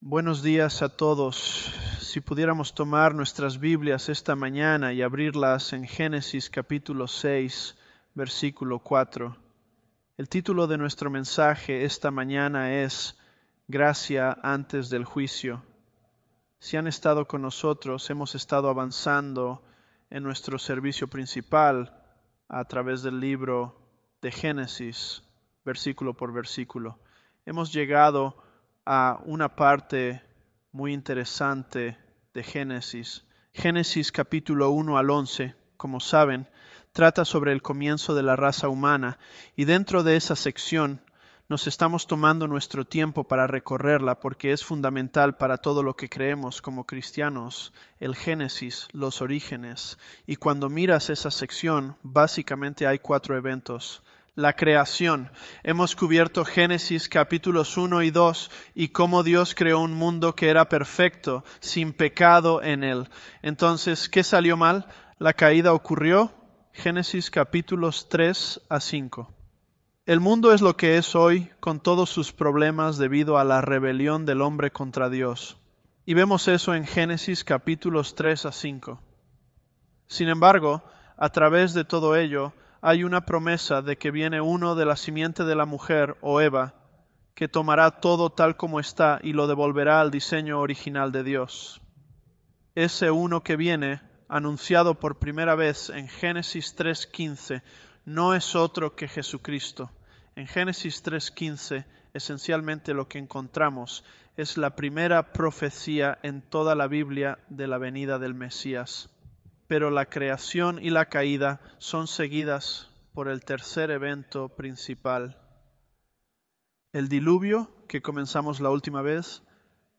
Sermons
ElevenLabs_Genesis-Spanish026b.mp3